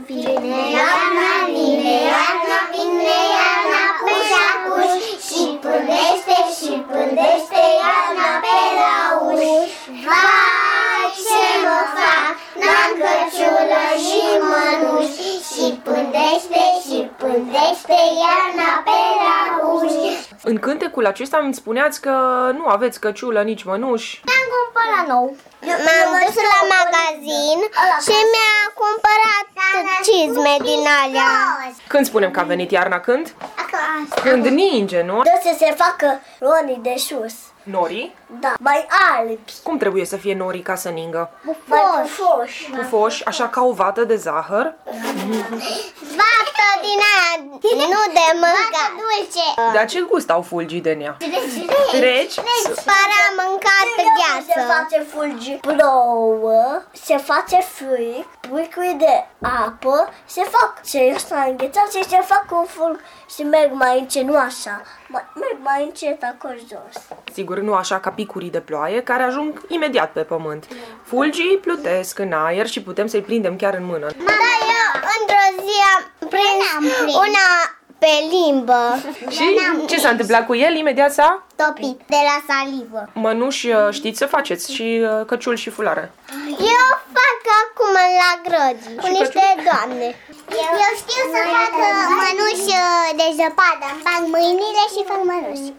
„Vine iarna acuș acuș…ne pândește pe la uși” cântă copiii de la grădinița „Licurici” din Târgu Mureș.